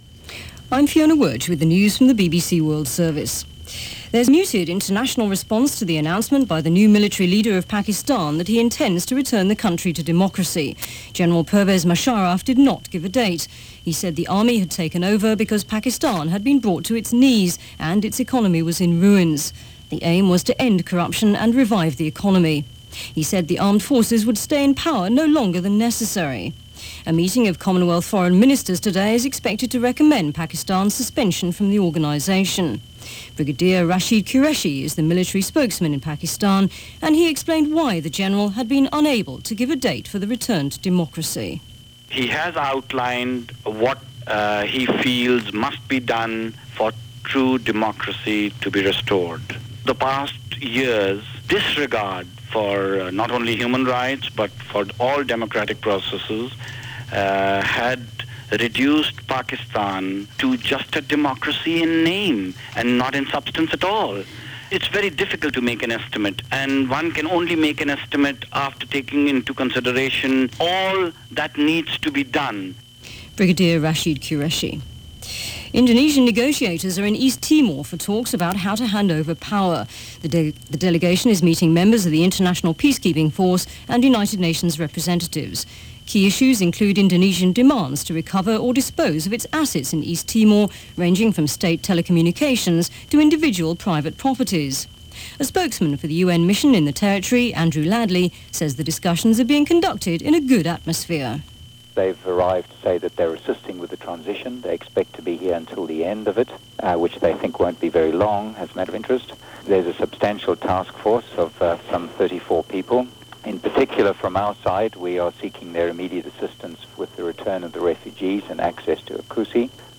October 18, 1999 – BBC World News –